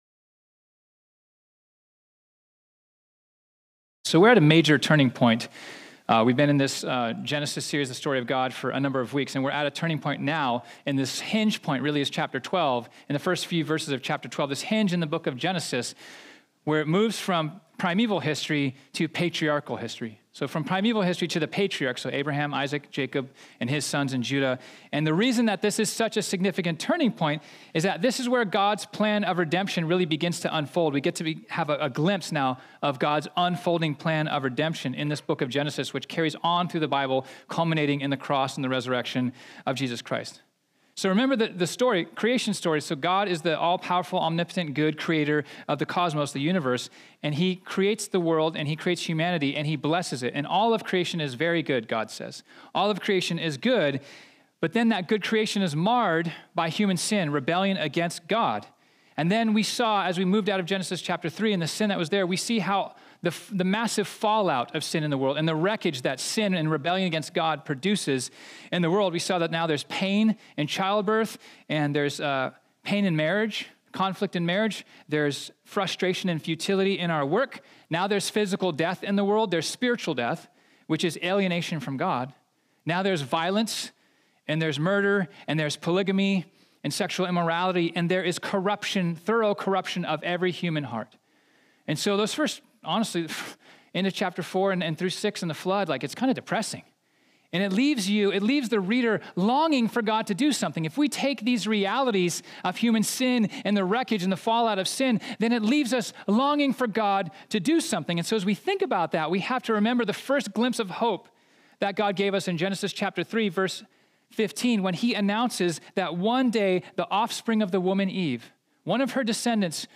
This sermon was originally preached on Sunday, February 10, 2019.